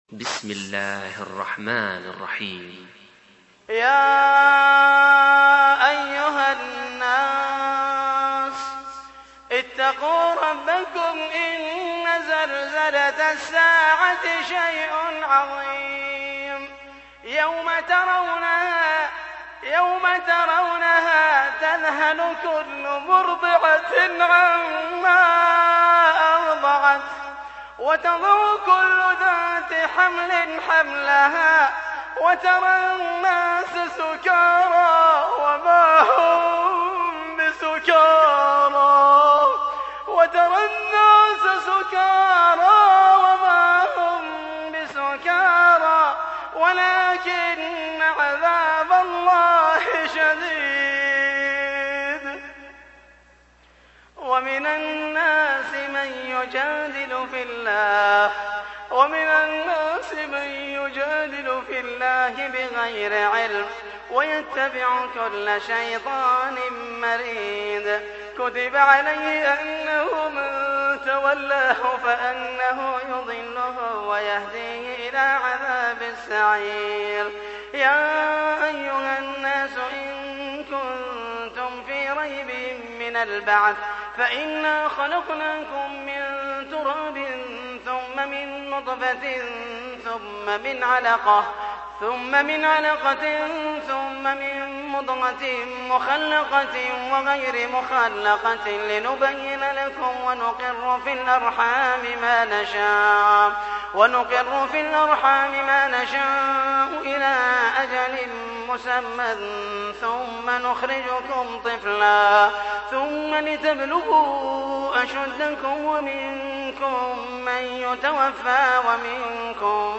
تحميل : 22. سورة الحج / القارئ محمد المحيسني / القرآن الكريم / موقع يا حسين